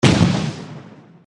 boom.mp3